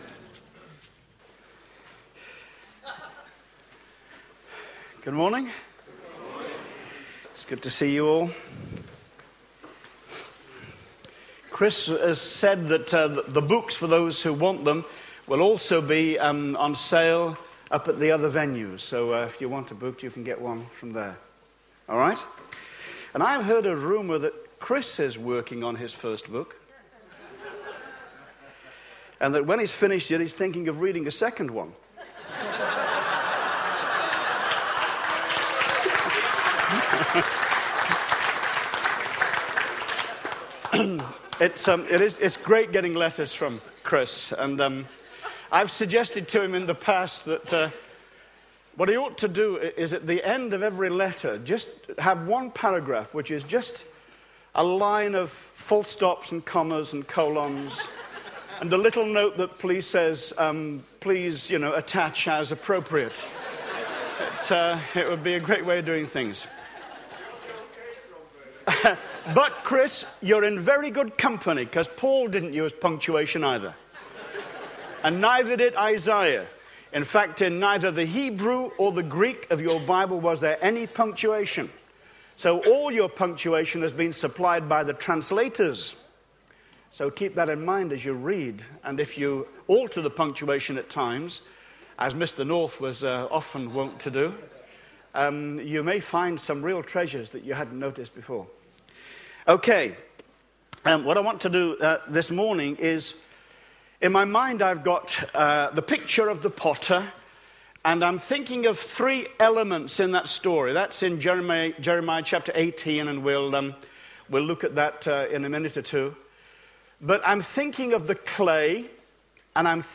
Message
It was taught during the morning session at the New Life Conference in Rora, Devon, United Kingdom.